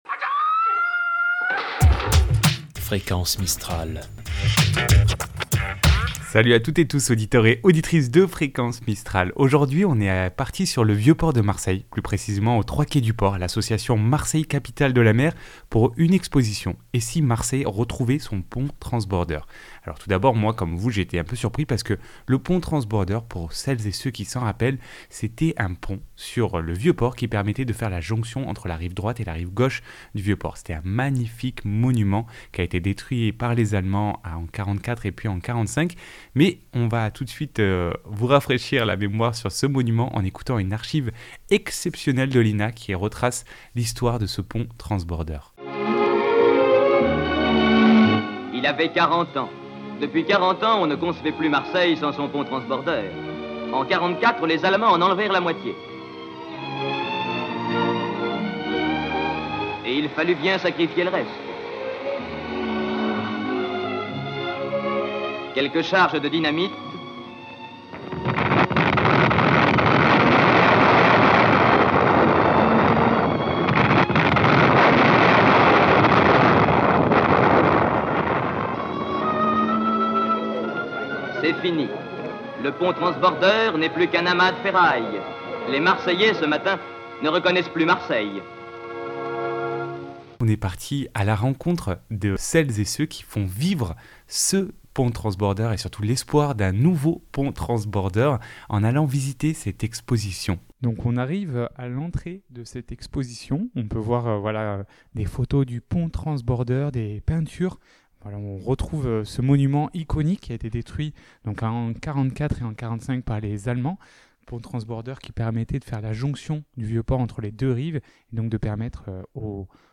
Et si marseille retrouvait son pont transbordeur reportage .mp3 (19.4 Mo)